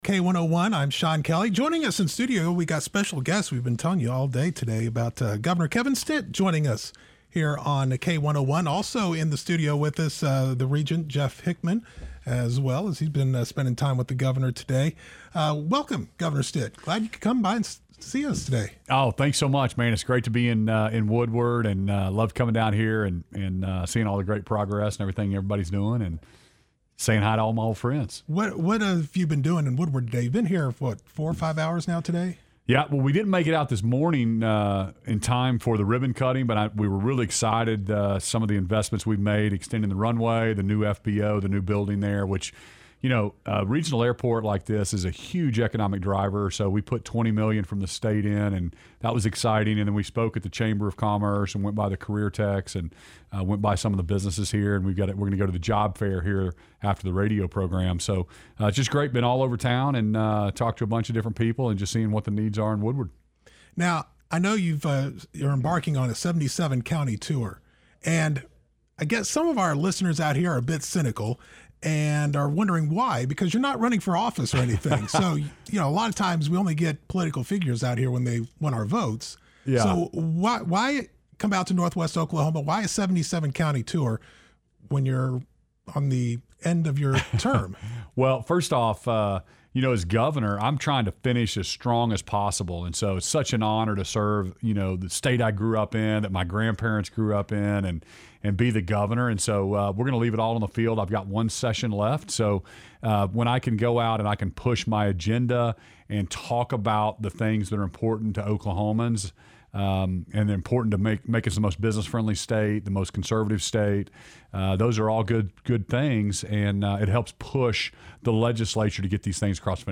ICYMI: GOV. KEVIN STITT VISITS K101 & INTERVIEW | K-1O1